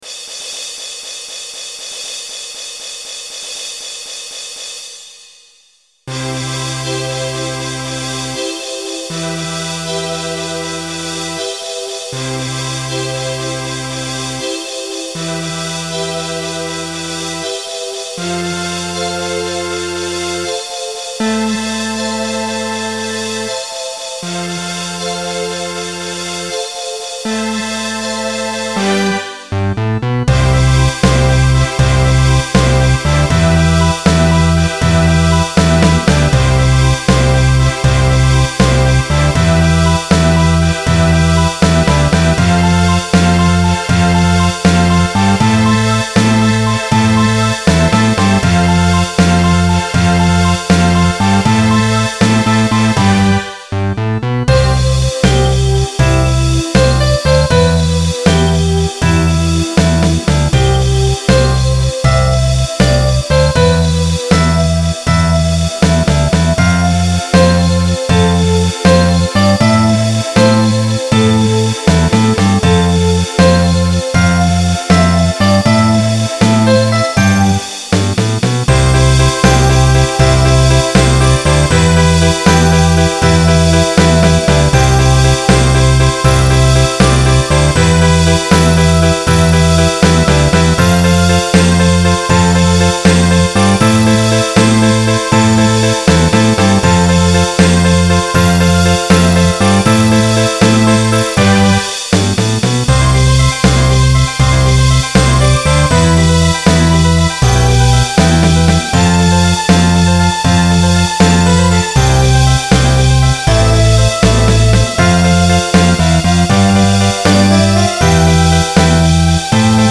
I am reposting some of my early MIDI files with changes directional stereo sound.
BALLADS